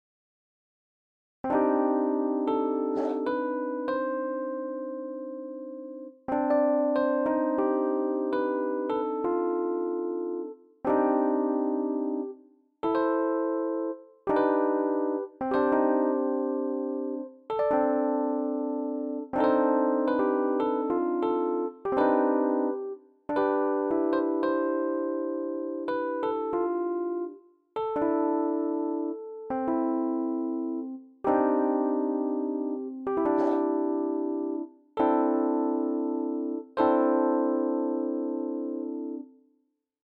RHODES-jRhodes3.mp3